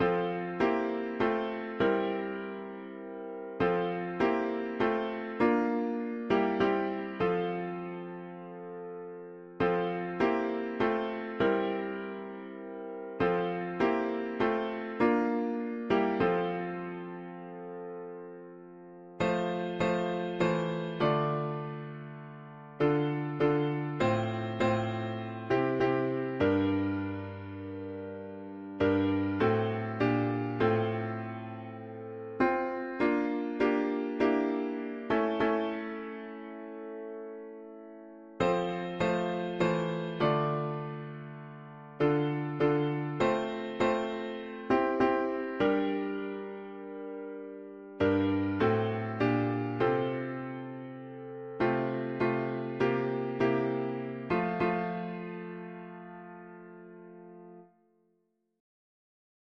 Lyrics: Be still, my soul; the Lord is on thy side; bear patiently the cross of grief or pain; leave to thy God to order and provide; in every change he faith… english theist 4part
Key: F major